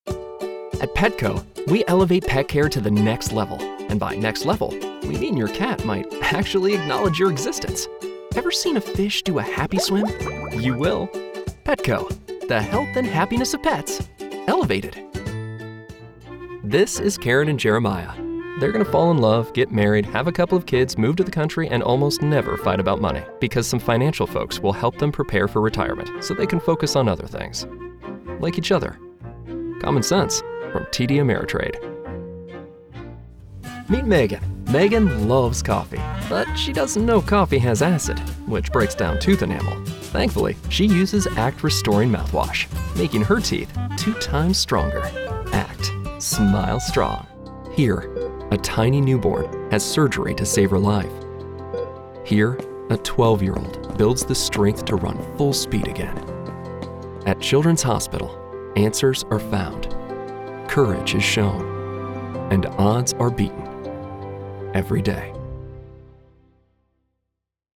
English - Midwestern U.S. English
Midwest, Neutral
Young Adult
Middle Aged